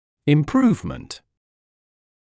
[ɪm’pruːvmənt][им’пруːвмэнт]улучшение